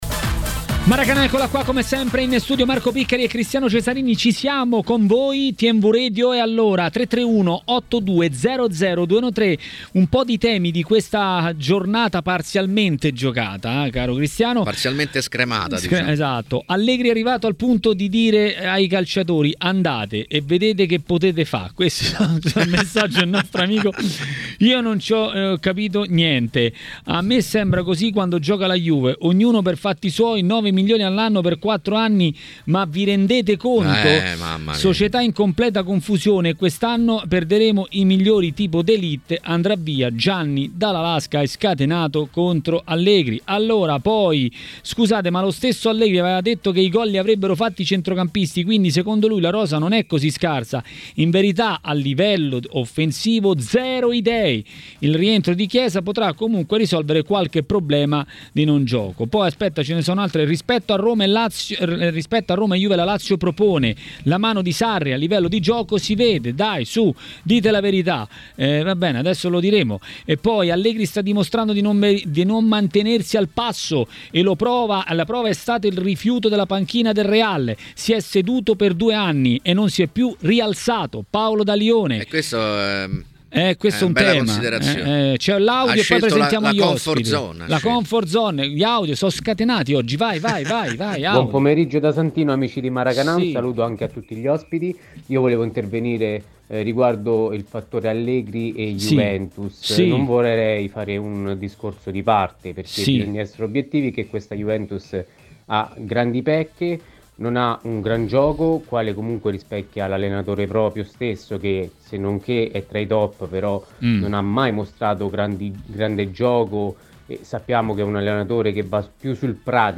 A commentare il turno di campionato a TMW Radio, durante Maracanà, è stato l'ex calciatore e tecnico Alessio Tacchinardi.